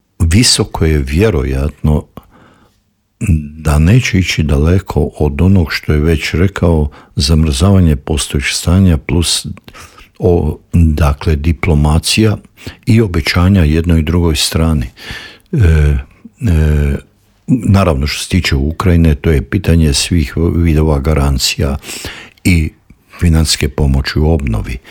ZAGREB - Dinamična politička zbivanja u svijetu tema su i novog Intervjua Media servisa.